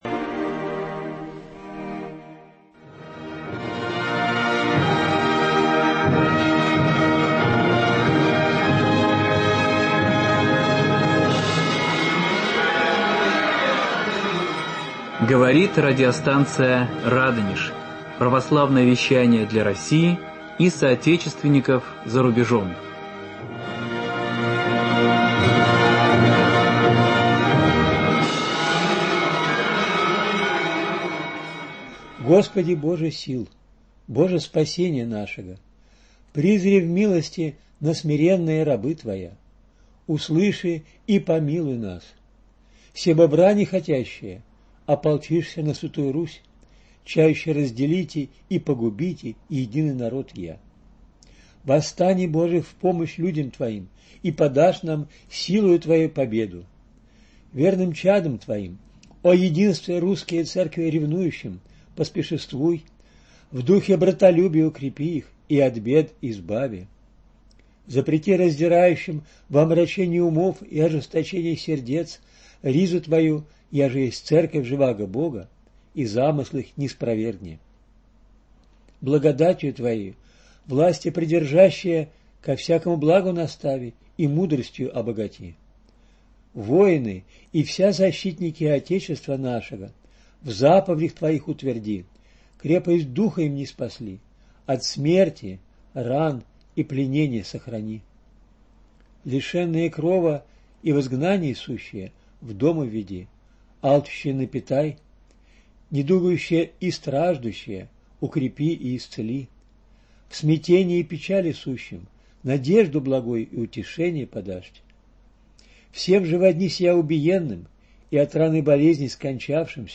В эфире беседа